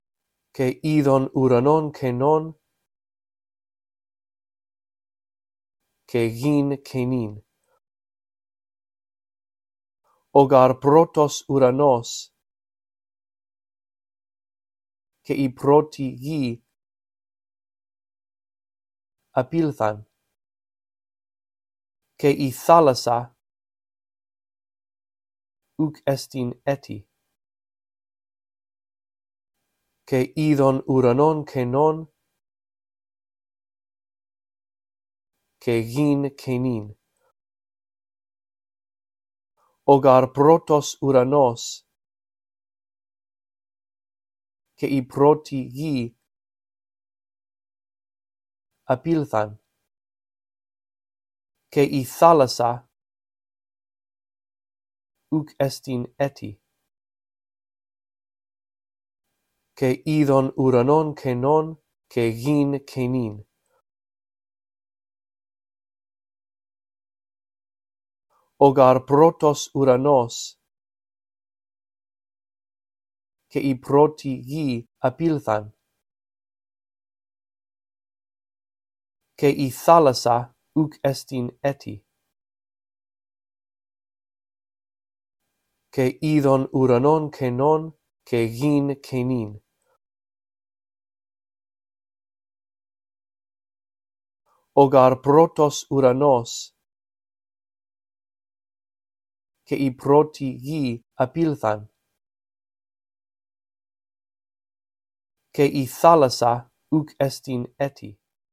In this audio track, I read through verse 1 a phrase at a time, giving you time to repeat after me. After two run-throughs, the phrases that you are to repeat become longer.